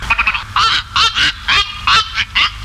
Grande Aigrette
Egretta alba
aigrette.mp3